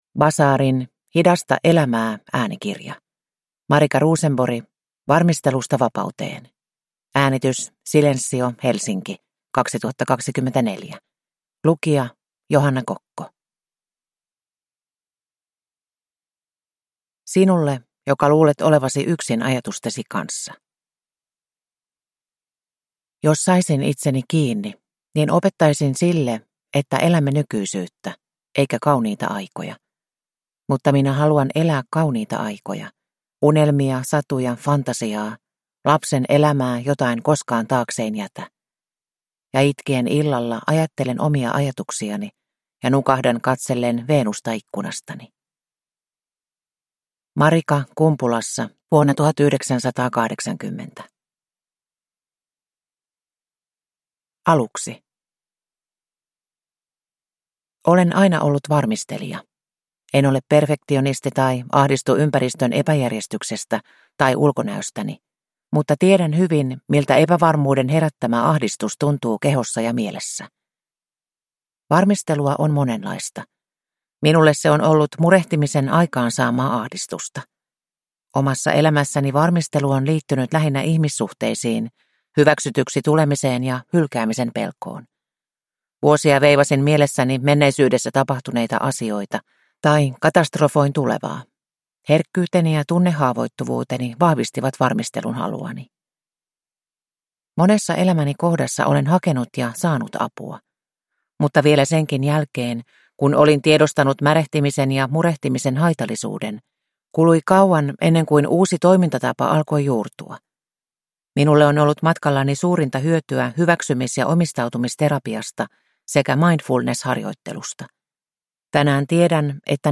Varmistelusta vapauteen – Ljudbok